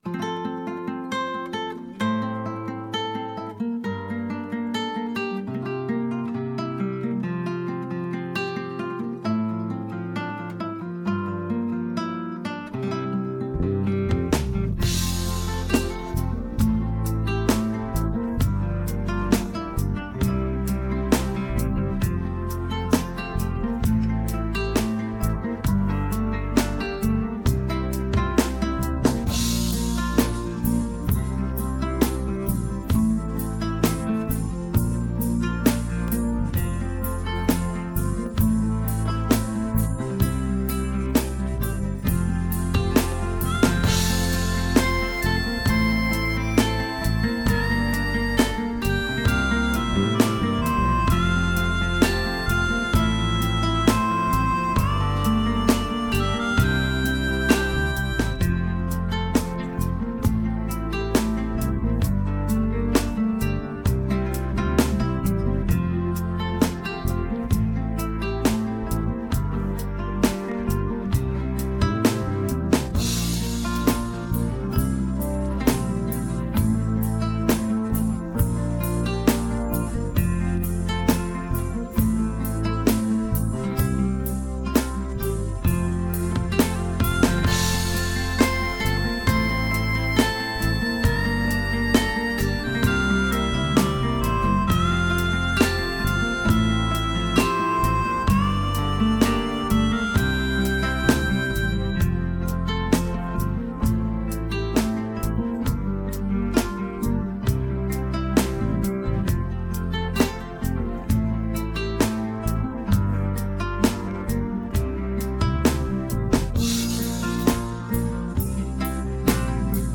Übungsaufnahmen - De lesde Dagen
Runterladen (Mit rechter Maustaste anklicken, Menübefehl auswählen)   De lesde Dagen (Playback - Dwarslopers)
De_lesde_Dagen__4_Playback_Dwarslopers.mp3